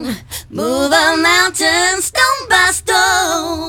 FEMVOC02.mp3